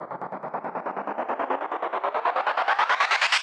MB Trans FX (15).wav